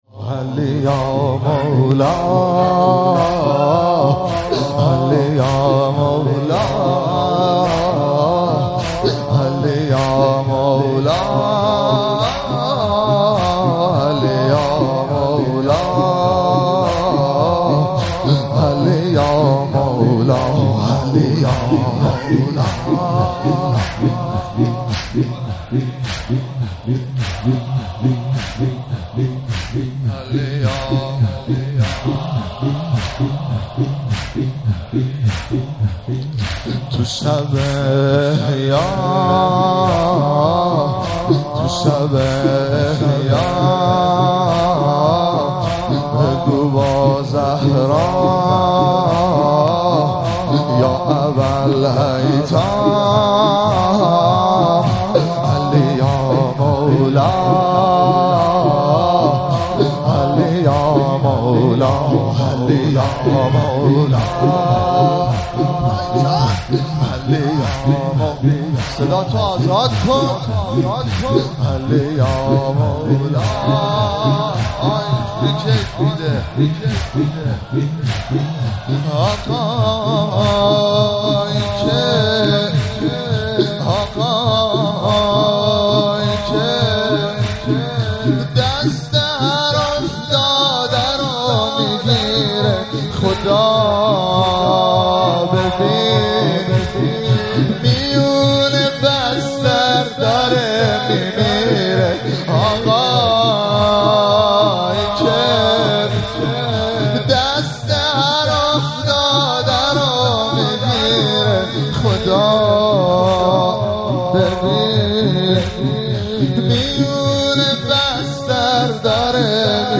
زمینه- تو شب احیا بگو با زهرا
مراسم مناجات خوانی و احیای شب نوزدهم ماه رمضان 1444